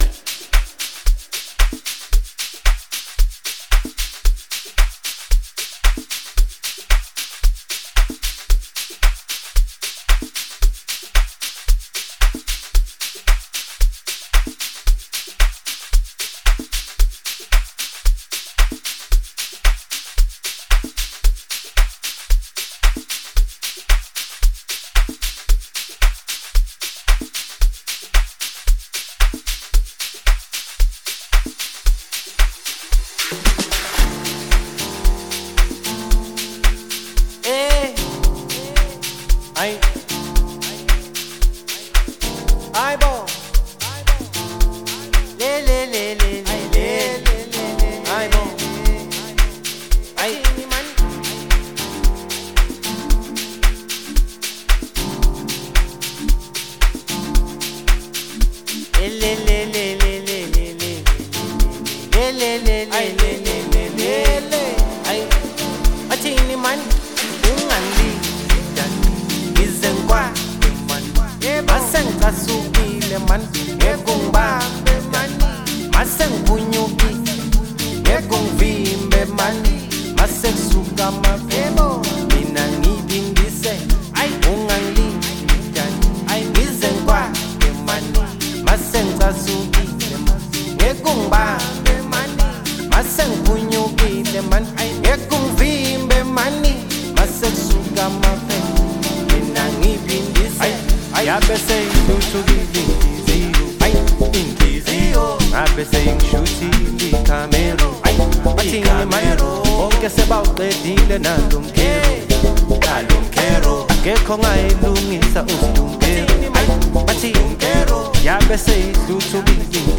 vibrant music tune
Amapiano songs